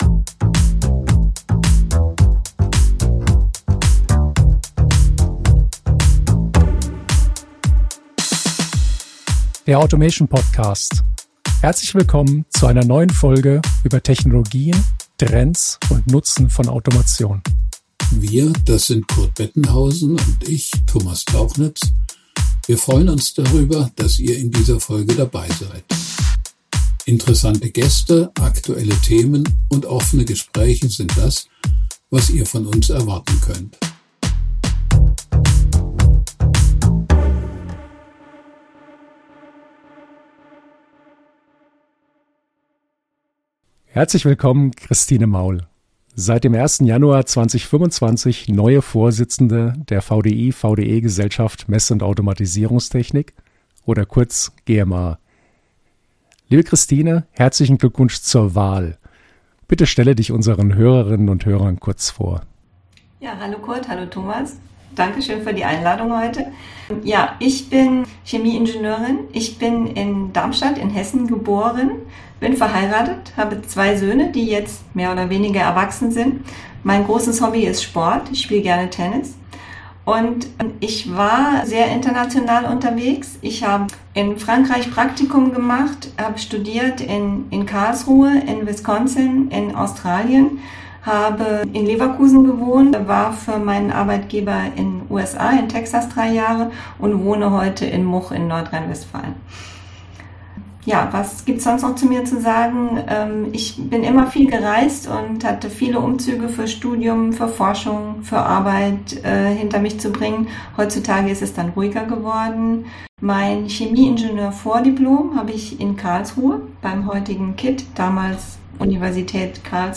Wir haben mit ihr über ihre bisherigen Aktivitäten in der GMA sowie die Schwerpunkte für ihre Amtszeit gesprochen.